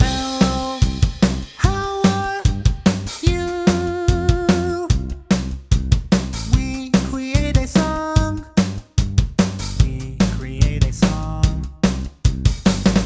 Hier ein kurzer Test, hat ca. 10 Minuten gekostet. Jay & Keela (Lucy klingt für mich künstlicher als Keela), für's Skizzieren find ich's praktisch, in der richtigen Tonlage klingt's, in der falschen weniger (siehe Jay C2 vs. C3).